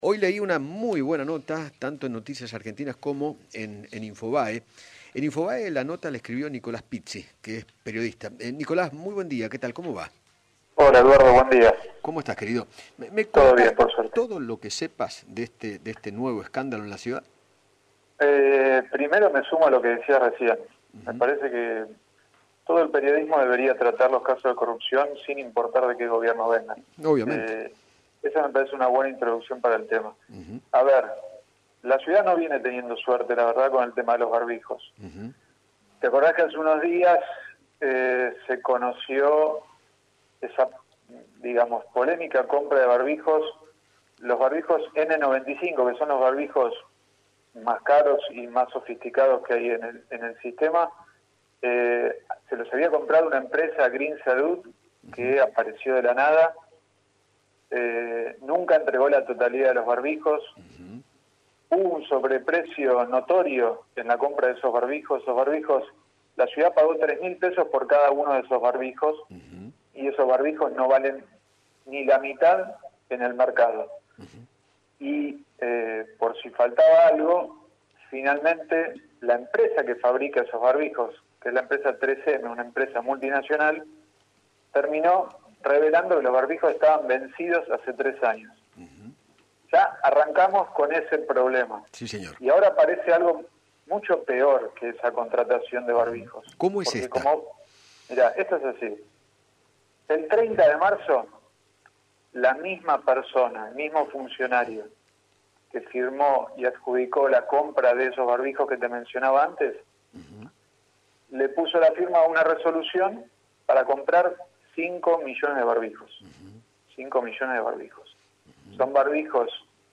dialogó con Eduardo Feinmann sobre la compra de 5 millones de barbijos que realizó el gobierno de la Ciudad de Buenos Aires el 30 de marzo pero que, al día de hoy, sólo fueron entregados 150 mil e incluso se pagó por adelantado la mitad del total de esa operación. Además, tras estos hechos quedó al descubierto que no se trataría de una empresa que fabrica estos insumos sino que se dedica a la comercialización, distribución, importación y exportación de equipos de informática.